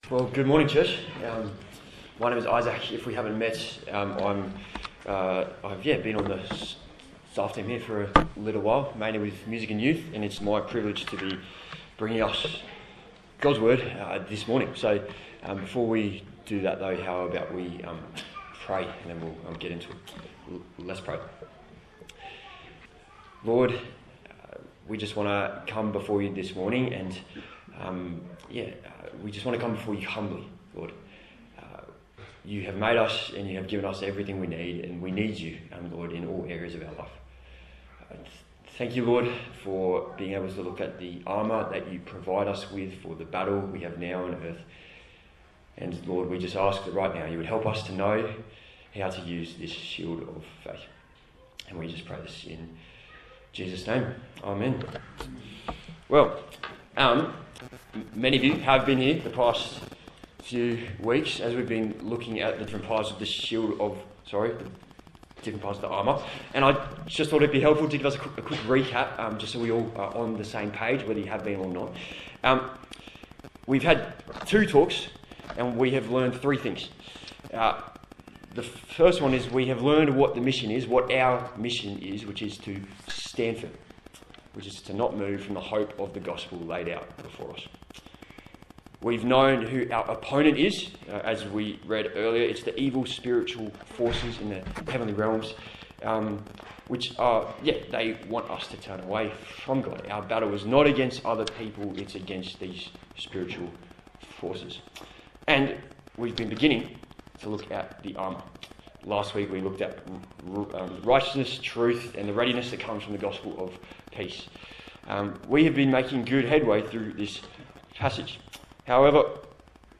Full Armour of God Passage: Ephesians 6:16, Romans 4 Service Type: Sunday Morning